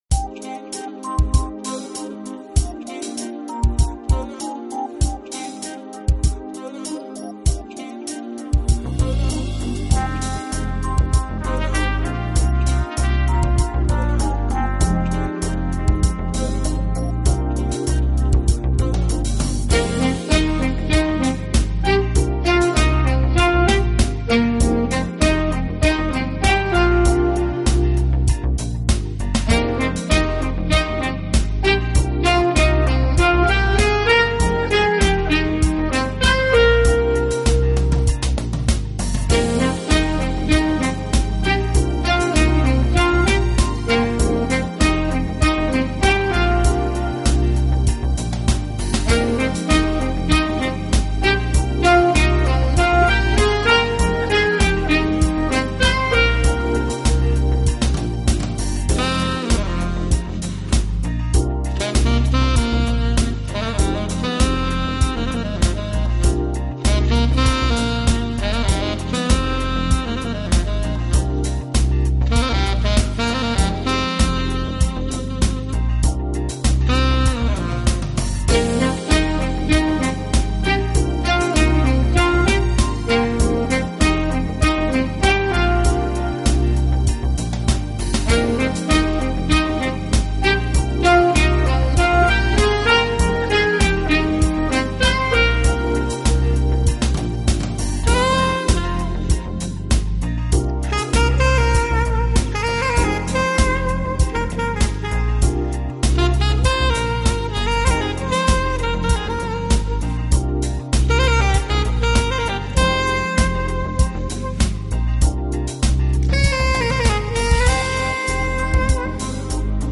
用萨克管演奏情调爵士乐，上世纪六十年代开始很走红，到上个世纪七十年